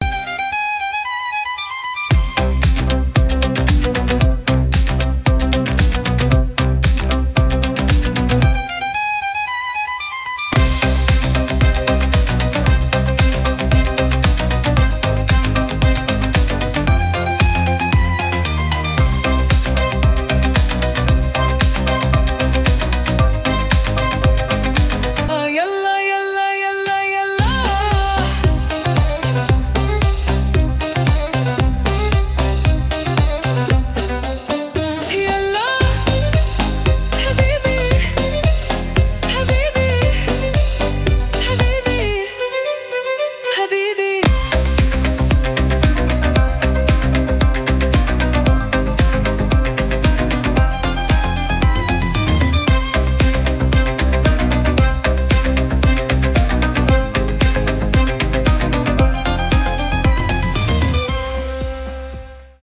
Танцевальные рингтоны
восточные , арабские , скрипка , house